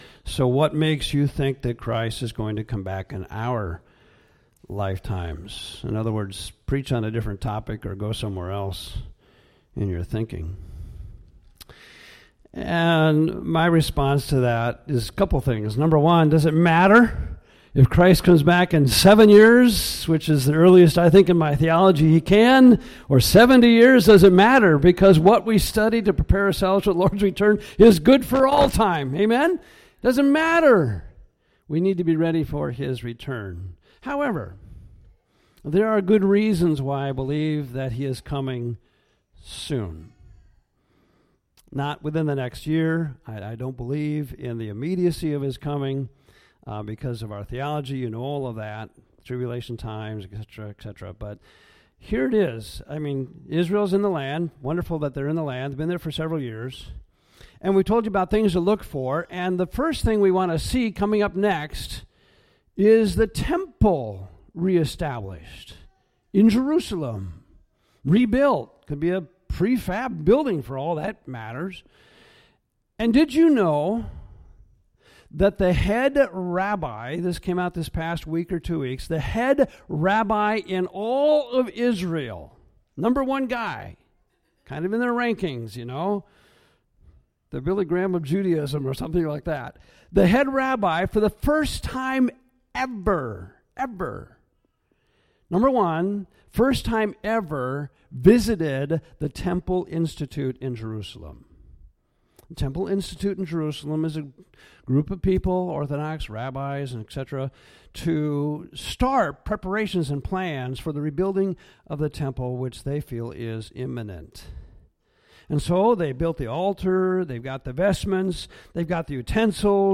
Advanced Training for Christian Soldiers - Prepping for Christ's Return. This sermon series covers life-saving biblical principles to enable believers to be Overcomers in the days ahead.